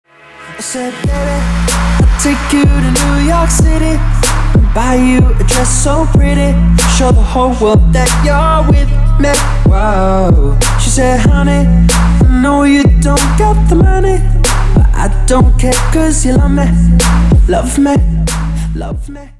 красивый мужской голос
Trap
Bass